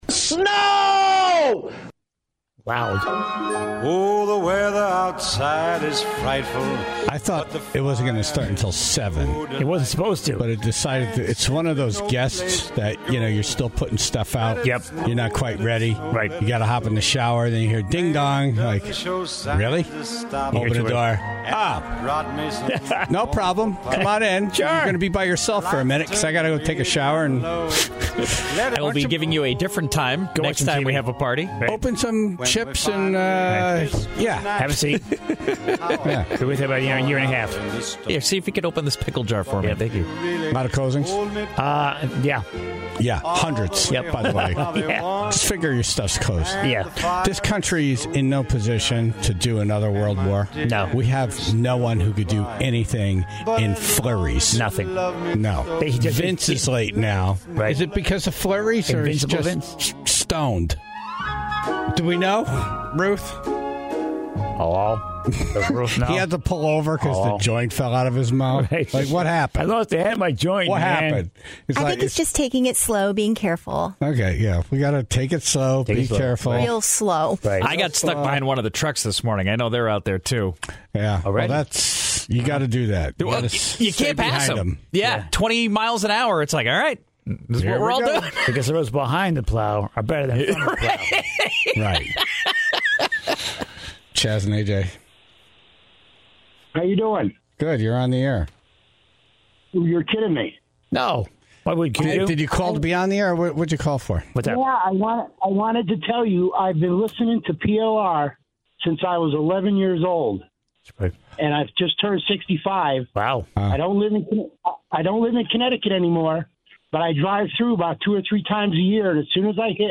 and took calls from the Tribe about the road conditions.